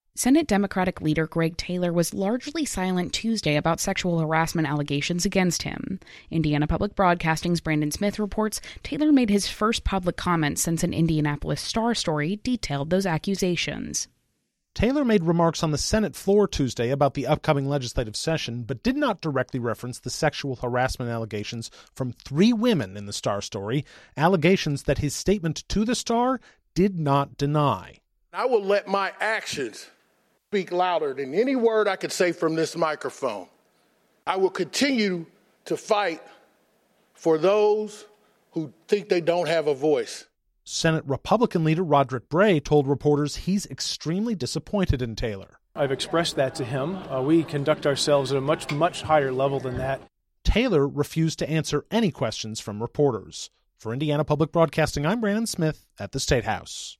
Sen. Greg Taylor (D-Indianapolis) read a prepared statement to reporters at the General Assembly's Organization Day Tuesday, but refused to answer any questions.